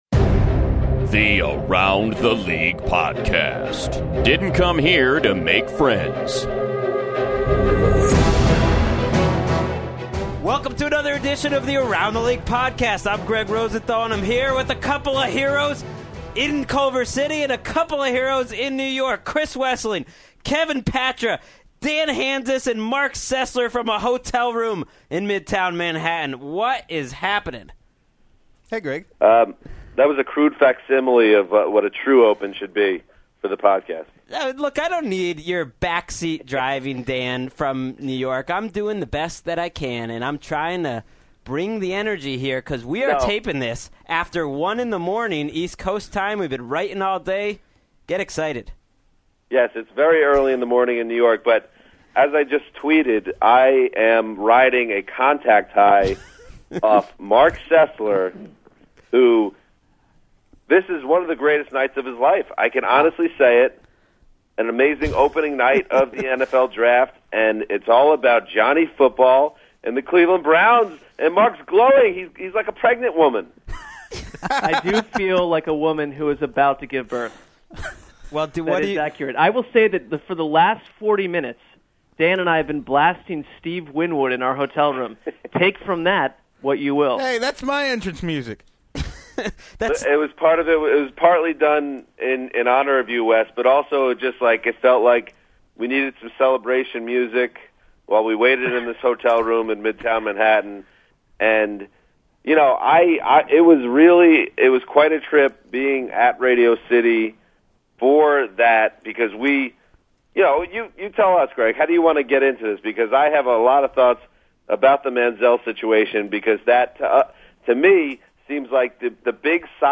live on the scene in New York